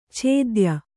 ♪ chēdya